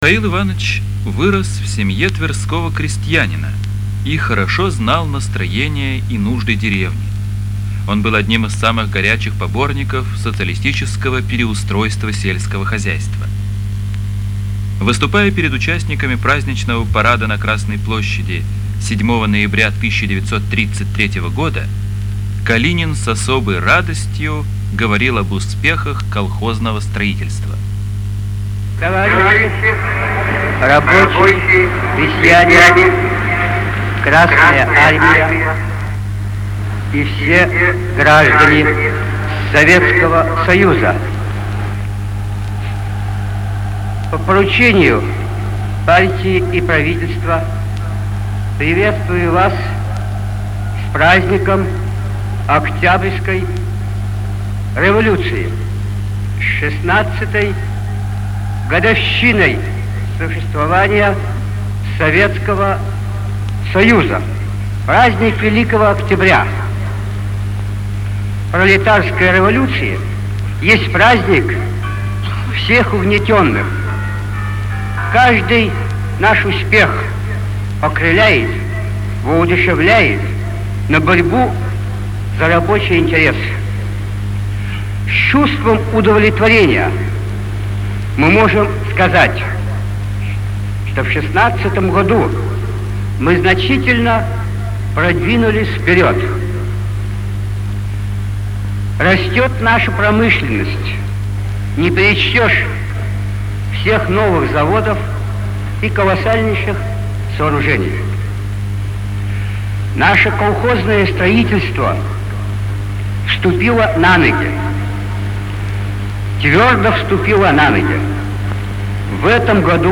Название: "Из речи 7 ноября 1933 года на Красной площади"